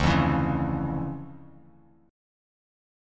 DmM9 chord